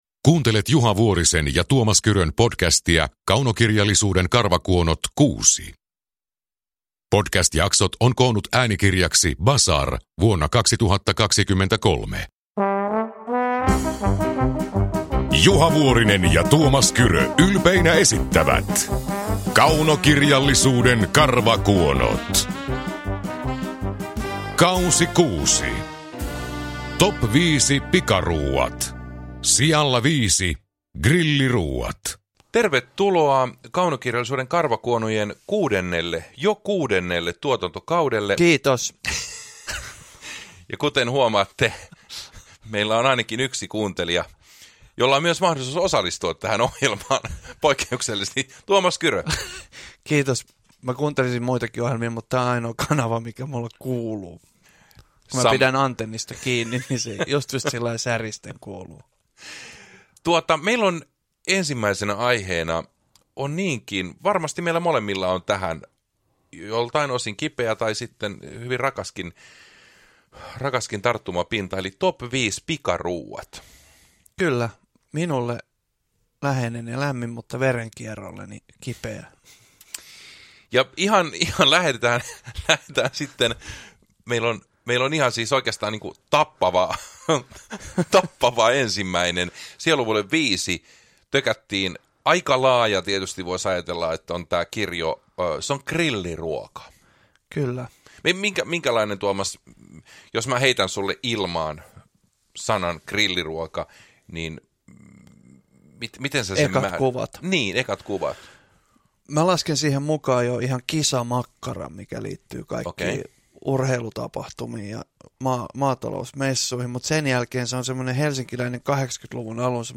Uppläsare: Tuomas Kyrö, Juha Vuorinen